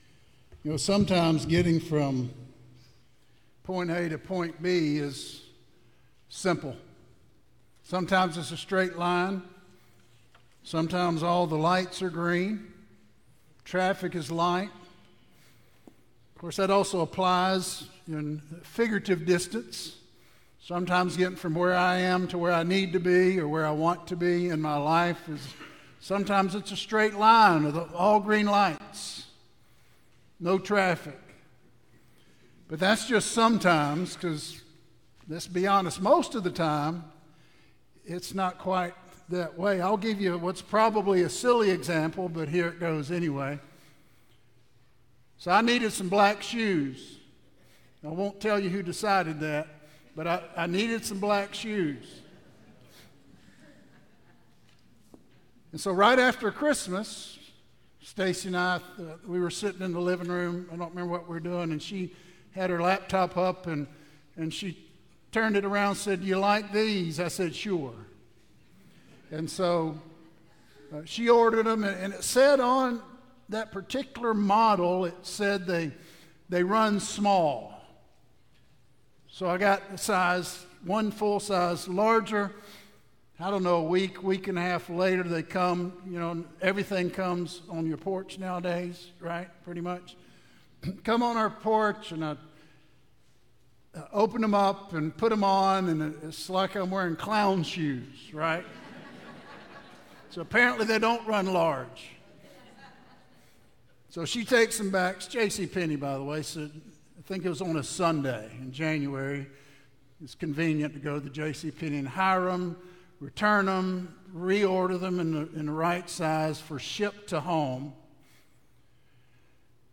Sermons | West Metro Church of Christ